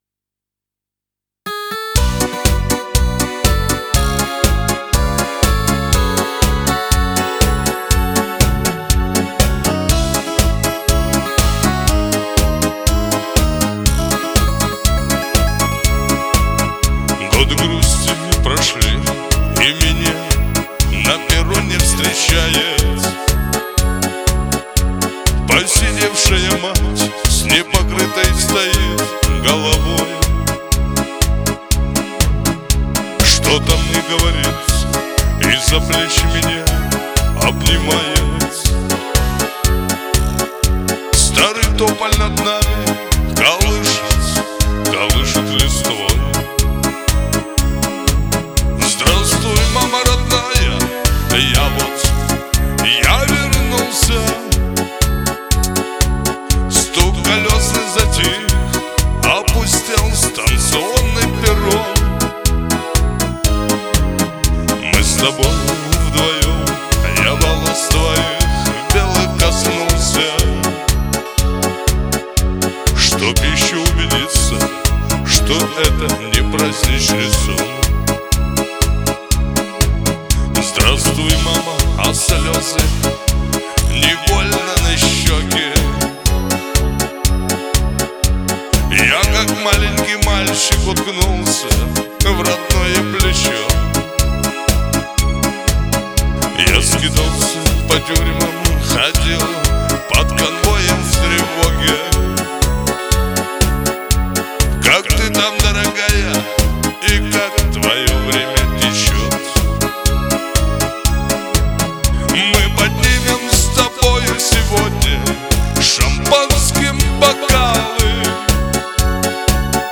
Жанр: Шансон